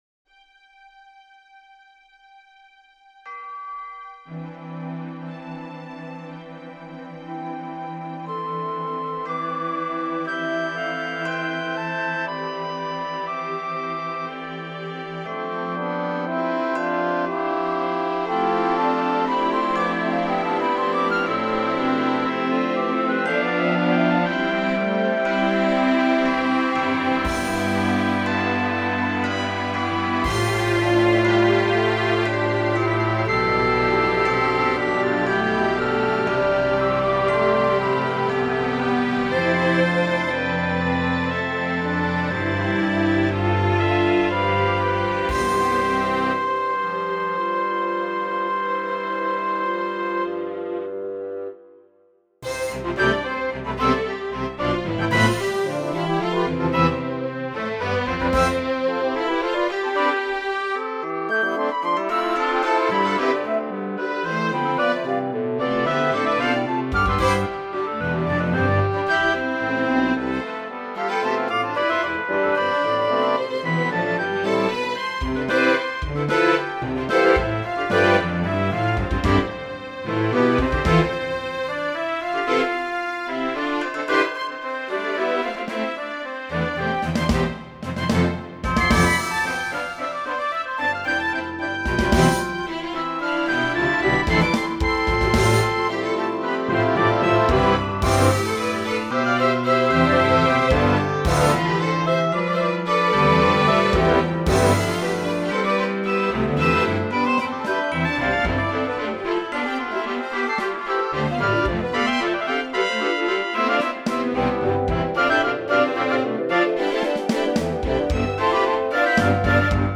"Bachlava" : for Orchestra
listen to a Sib simulation